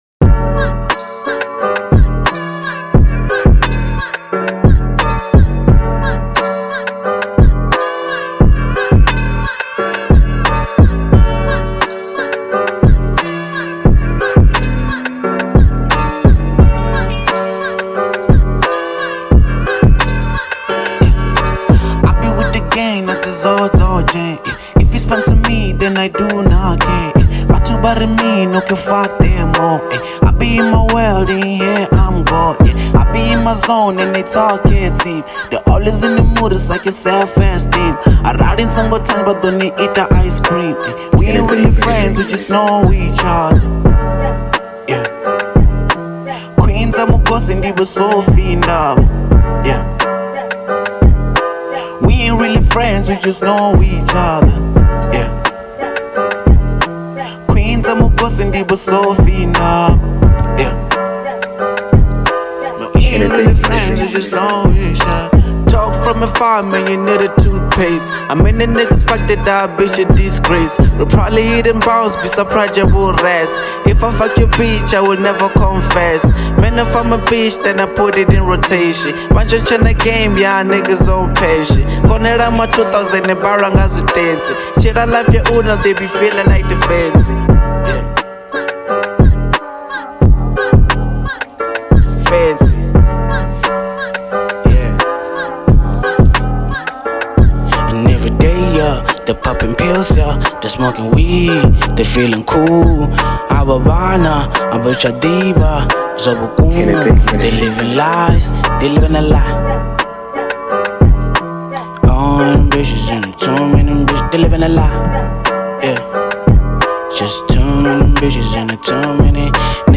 Genre : Venrap